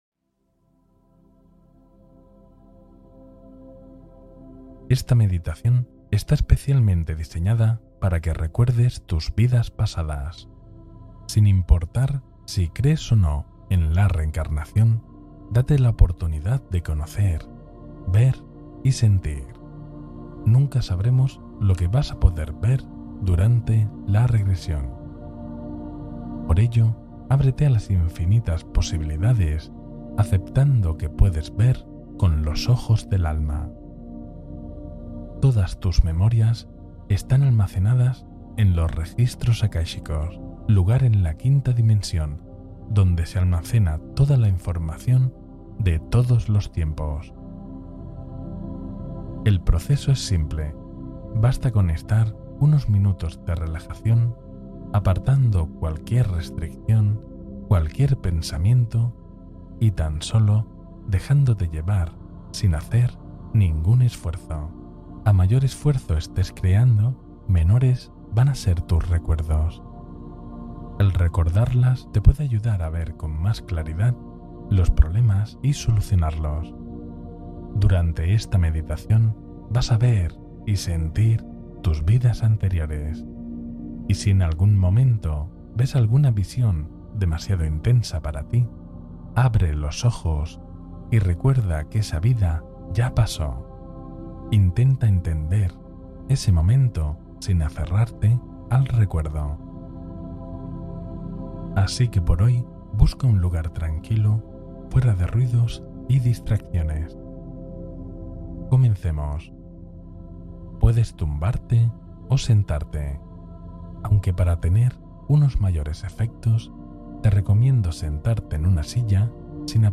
Explora memorias profundas con esta hipnosis guiada transformadora